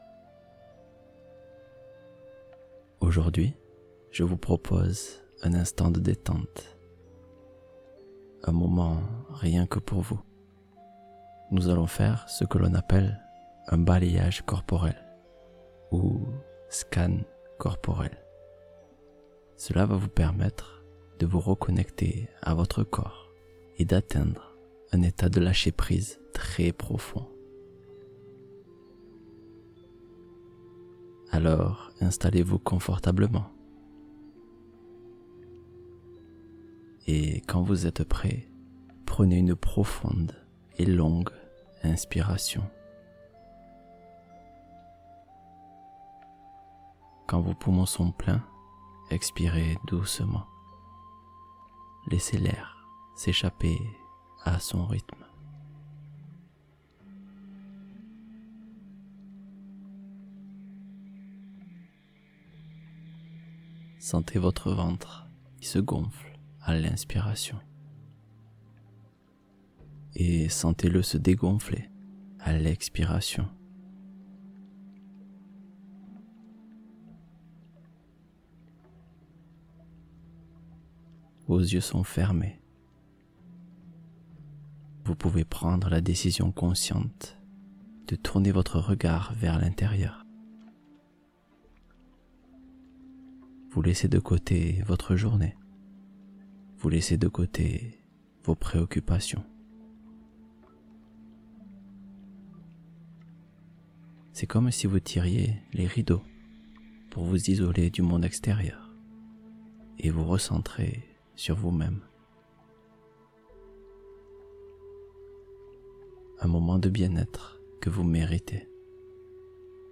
Lâchez Prise en 15 Minutes avec ce Scan Corporel Profond